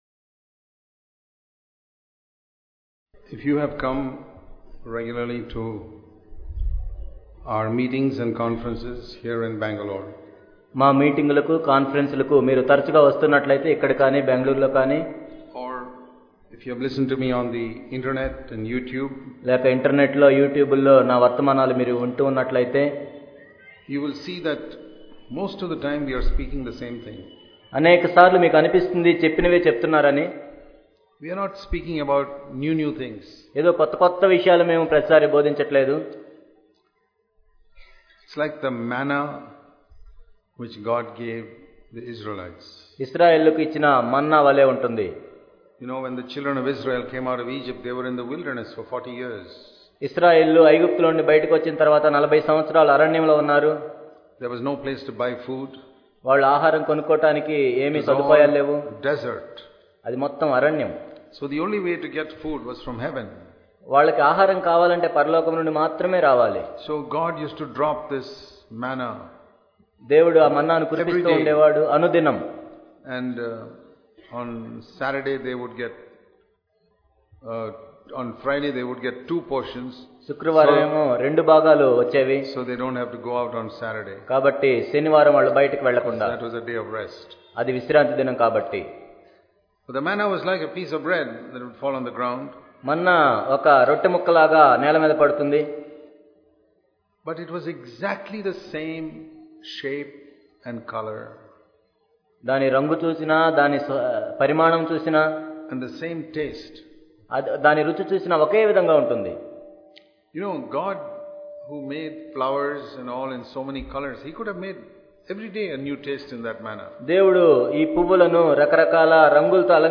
The New Covenant Church An Overcoming Life and True Fellowship Watch the Live Stream of the Hyderabad Conference 2015. Theme: An Overcoming Life and True Fellowship. 28th & 29th November 2015.